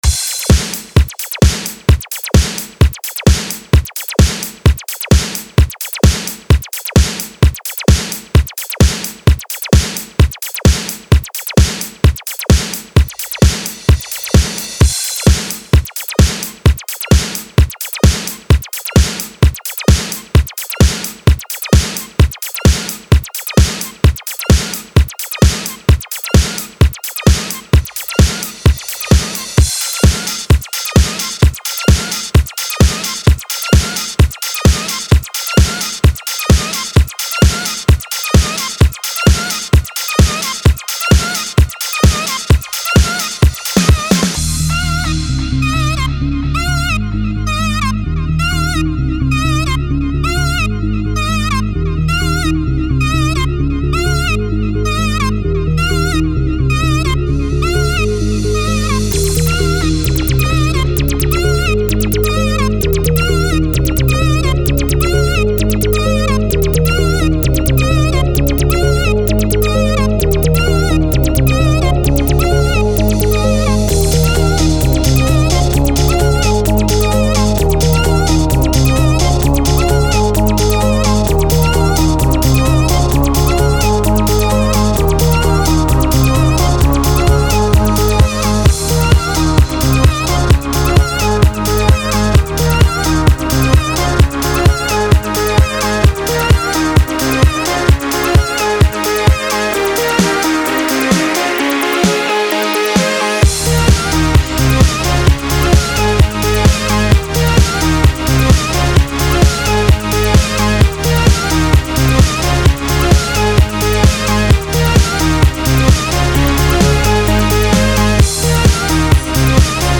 DJ/Producer